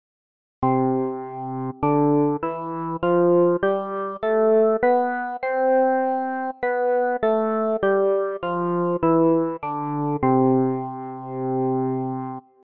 017：ドローバーオルガン（Drawbar Organ）
ＧＭ音源プログラムチェンジの１７番は、ドローバー・オルガン（Drawbar Organ）の音色です。
ですので、ここでは一般的な電気オルガンの音色が設定されています。